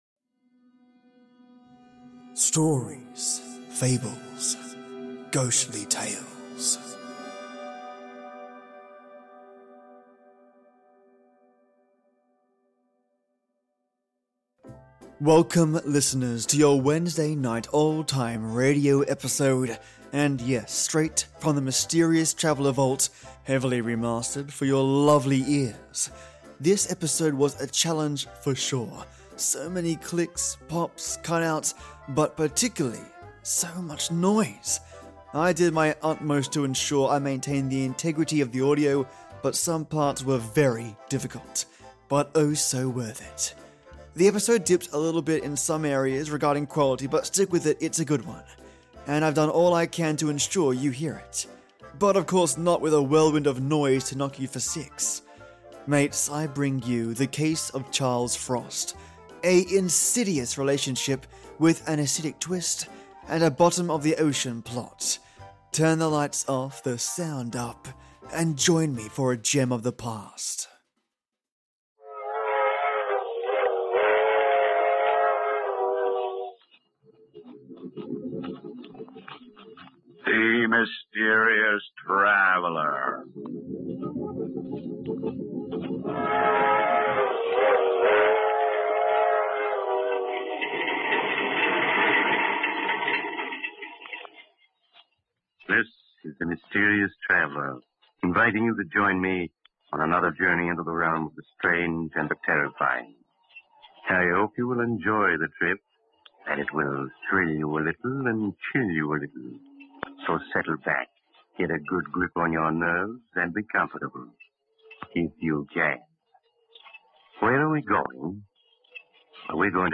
And yes, straight from the Mysterious Traveller Vault, heavily remastered for your lovely ears.
So many clicks pops, cut outs, but particularly, soooo much noise. I did my utmost to ensure I maintained the integrity of the audio but some parts were very difficult. The episode dips a little bit in some parts regarding quality, but stick with it, it’s a good one – and I’ve done all I can to ensure you hear it, but not with a whirlwind of noise to knock you for six. Mates I bring you – The Case of Charles Frost – a insidious relationships with an acidic twist, and a bottom of the ocean plot.